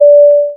alert.wav